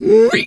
pig_2_hog_single_01.wav